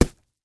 На этой странице собраны разнообразные звуки, связанные с манго: от мягкого разрезания ножом до сочного откусывания.
Звук манго на доске или столе